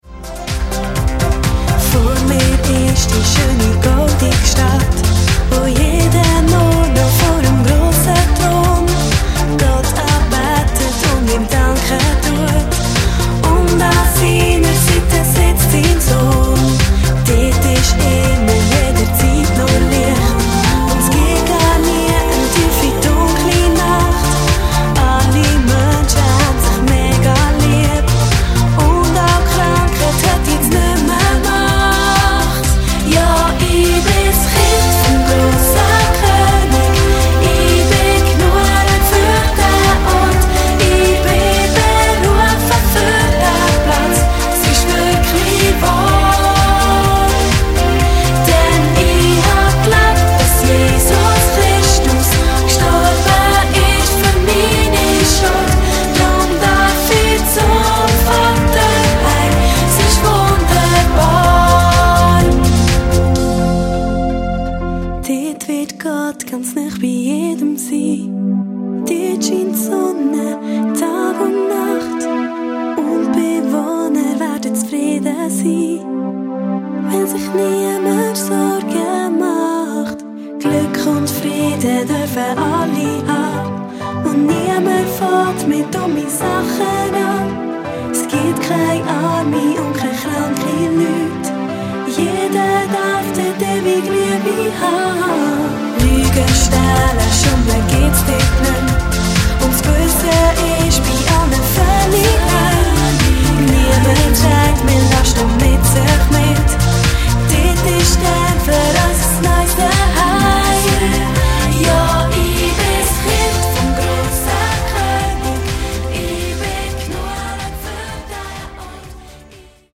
eingängige Balladen und poppige Melodien
tolle, zeitgemässe Arrangements